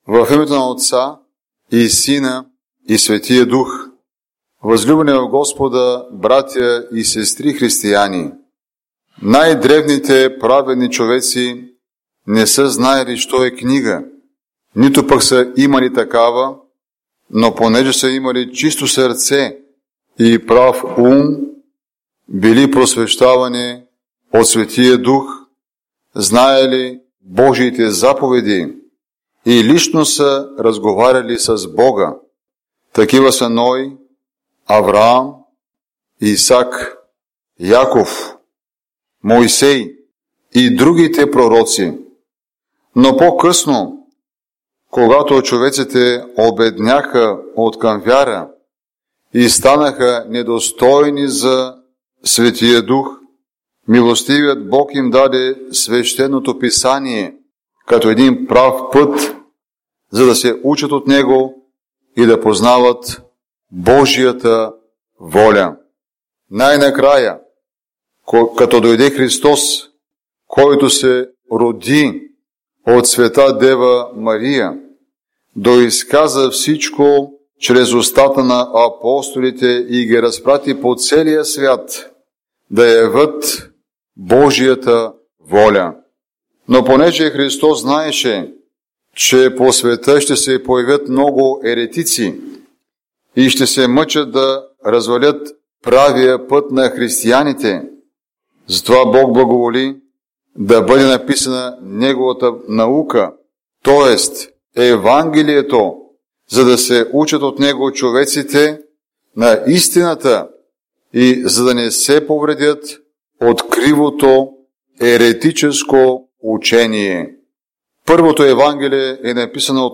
Неделя преди Рождество Христово - Проповед - Храм "Св.